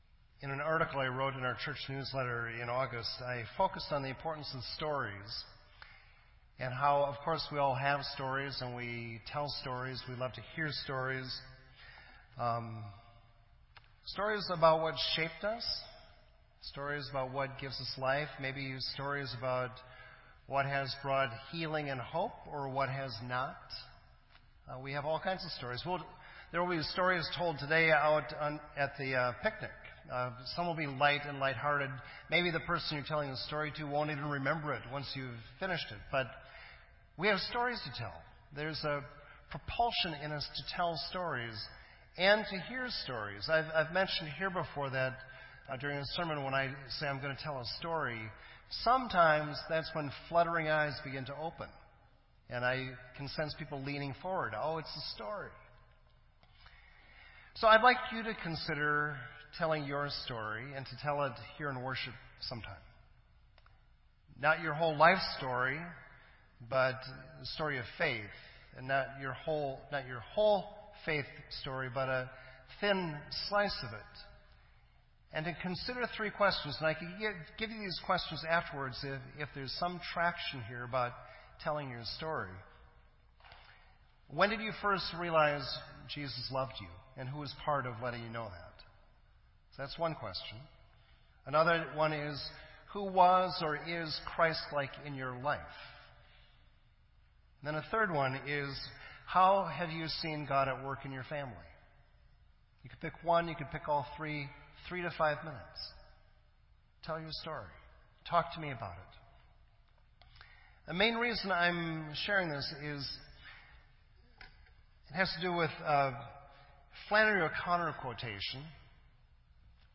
This entry was posted in Sermon Audio on September 18